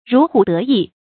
如虎得翼 注音： ㄖㄨˊ ㄏㄨˇ ˙ㄉㄜ ㄧˋ 讀音讀法： 意思解釋： 同「如虎傅翼」。